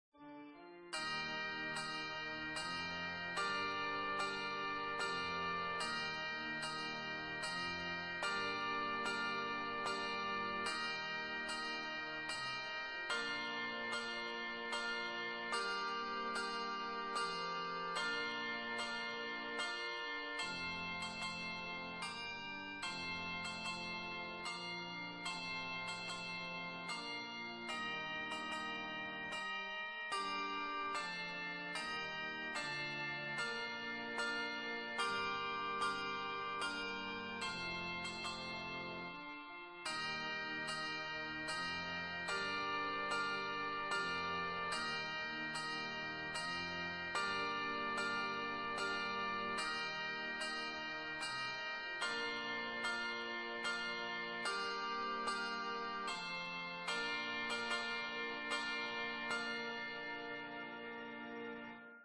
Handbells descant sample
A keyboard (organ or piano) accompaniment is also included.
Handbells/Handchimes For 2 octave handbells, 13 bells used.
Instrumental Keyboard / piano / organ accompaniment
Descant Simple chordal descant